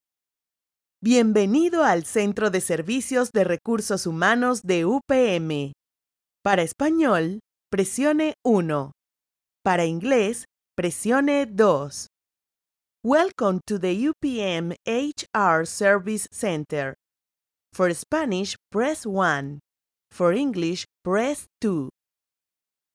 Female
Character, Confident, Conversational, Corporate, Friendly, Natural, Young
Microphone: Audio-Technica AT4030a Cardioid Condenser Microphone